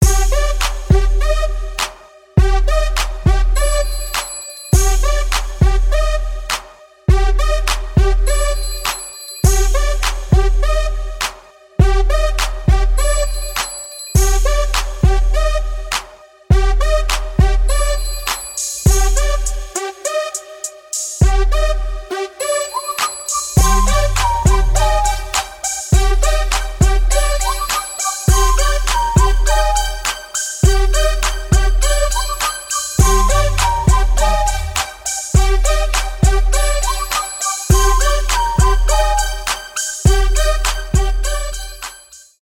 инструментальные , хип-хоп , без слов , рэп , свист
rnb